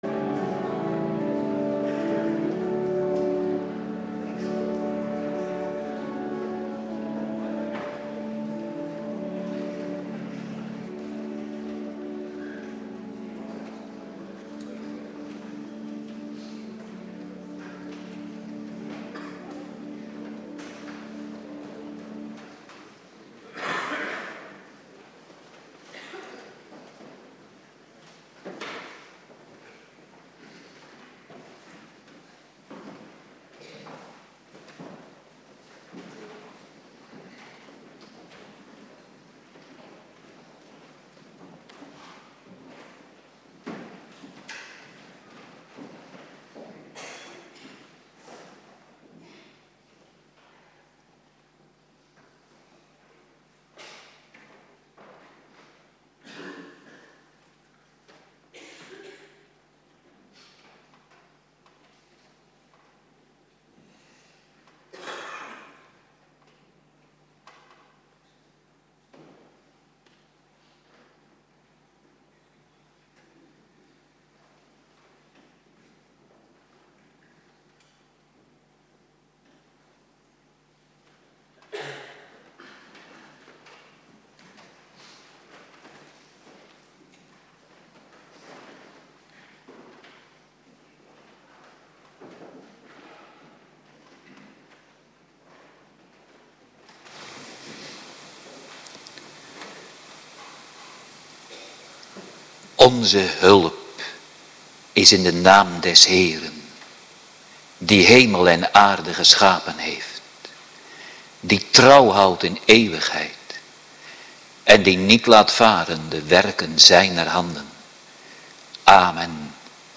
Locatie: Hervormde Gemeente Waarder